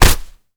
kick_hard_impact_08.wav